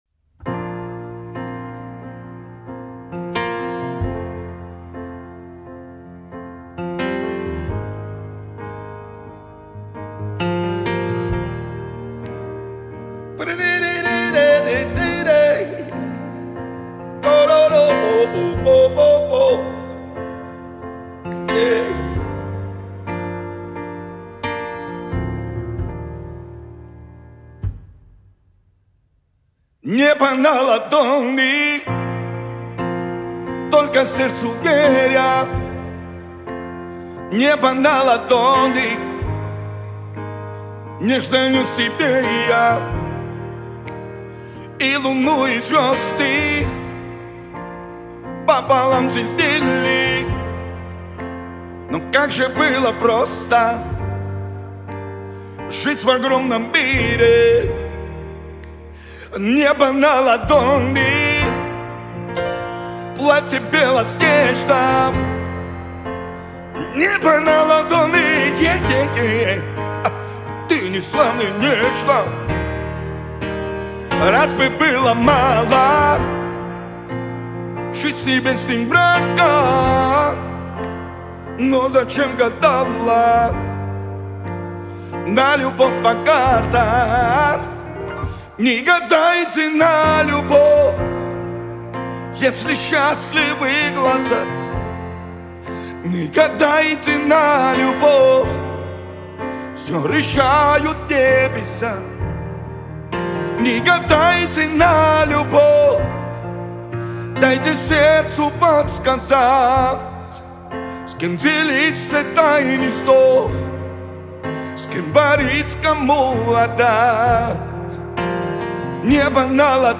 (Акустика) Live